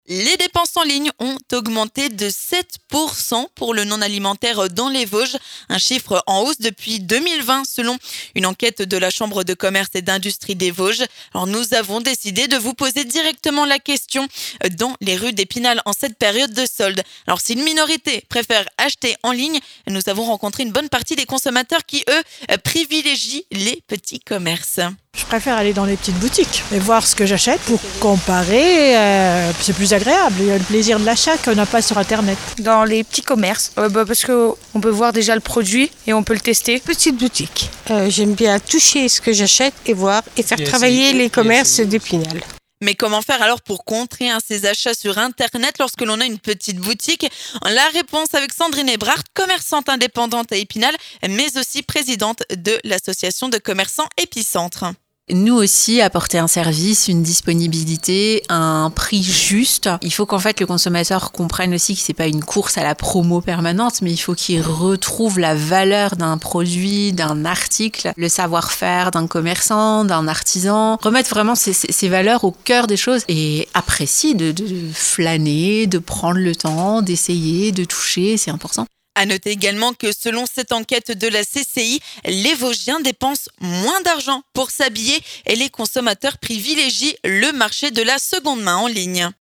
Nous avons décidé d’aller vous poser directement la question dans les rues d’Epinal en période de soldes !